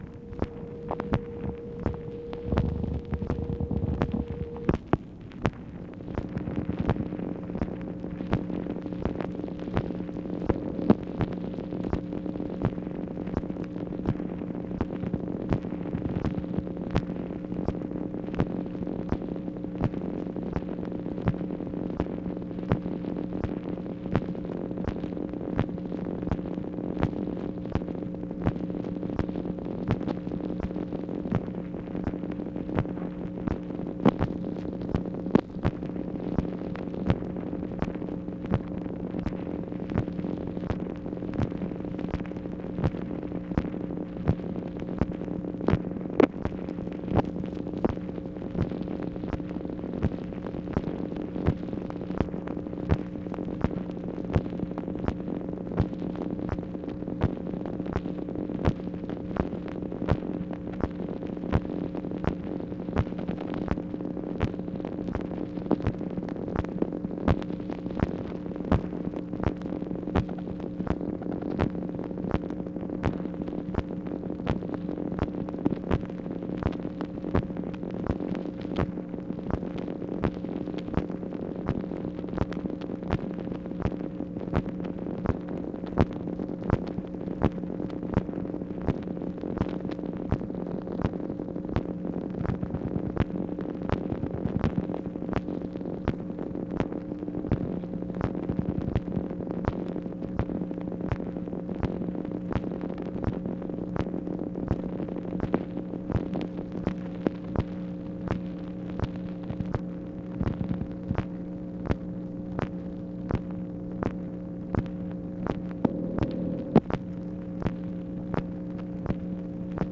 MACHINE NOISE, May 26, 1965
Secret White House Tapes | Lyndon B. Johnson Presidency